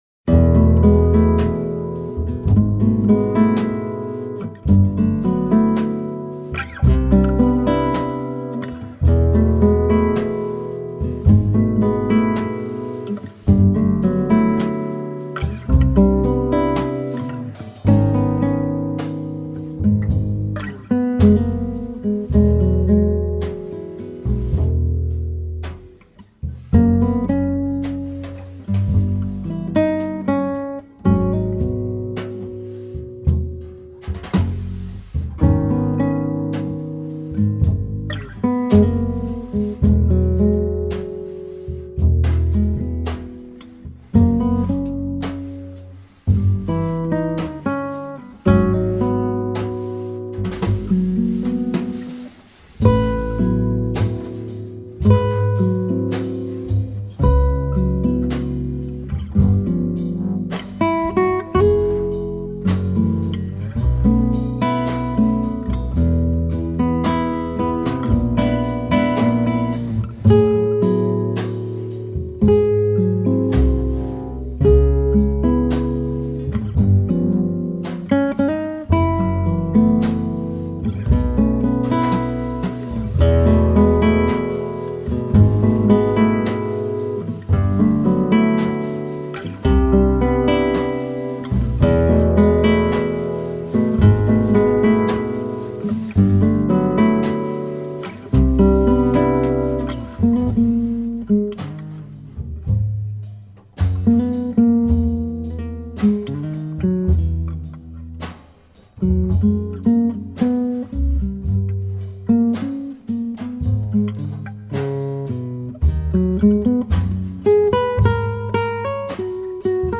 contrabbasso
chitarra acustica
percussioni
Registrato in presa diretta
armonizzazioni "Metheny-style"